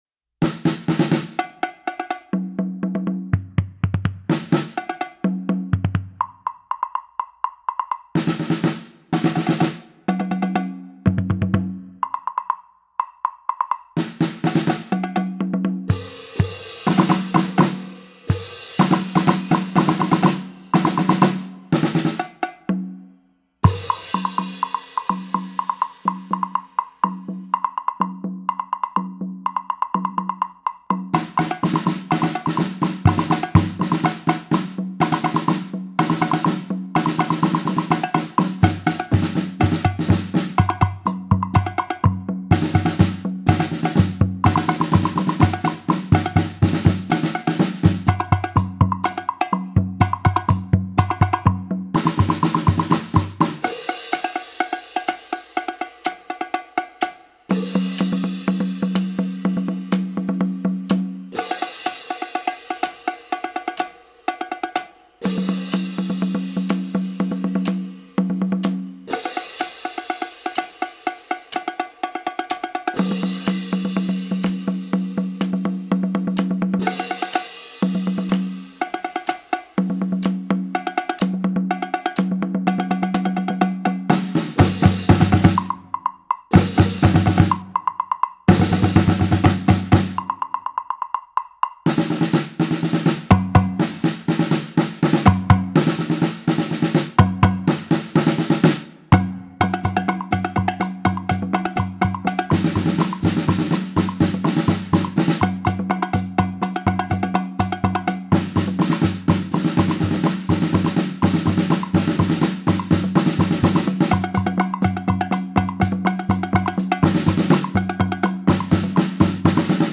Voicing: Percussion Sextet